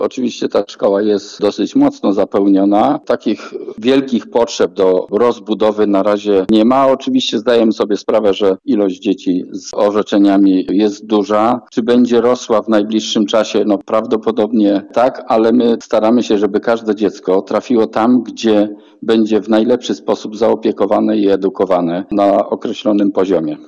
O komentarz poprosiliśmy wicestarostę stargardzkiego Marka Stankiewicza.